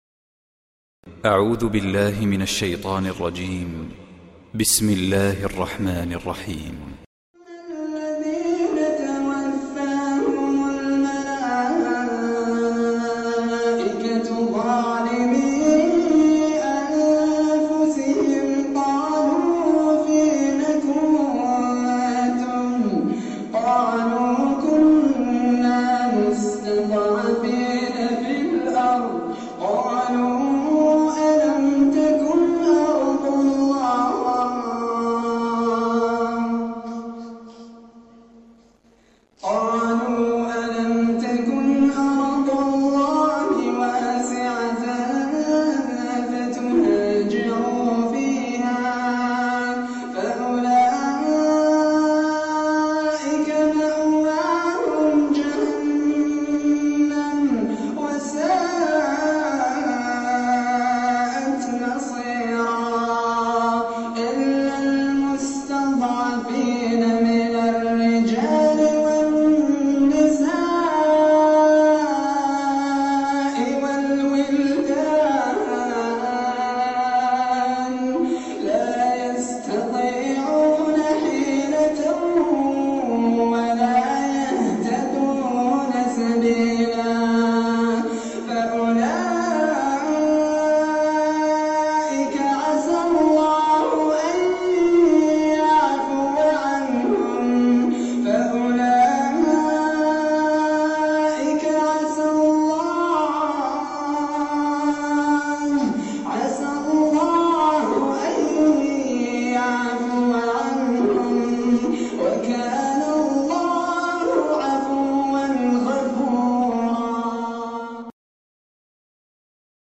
تلاوة خاشعة للقارئ الجزائري